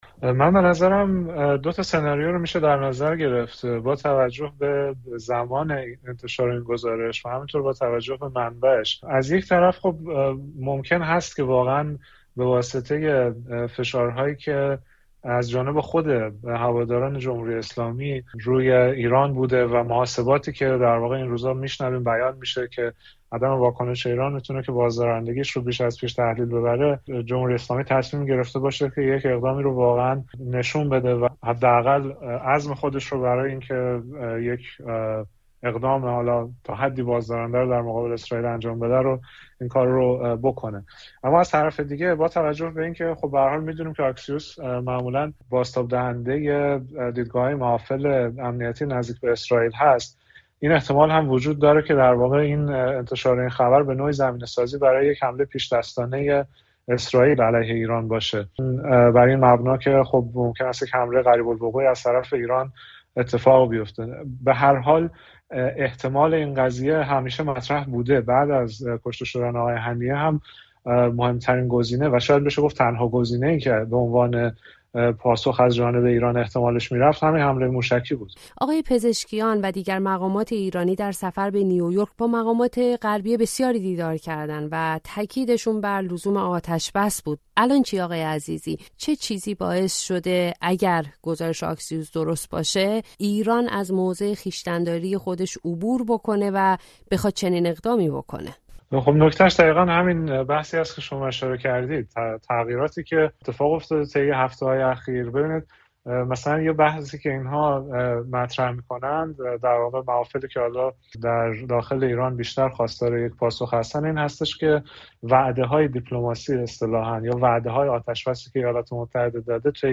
پیامدهای حمله احتمالی ایران به اسرائیل در گفتگو با یک تحلیلگر روابط بین‌الملل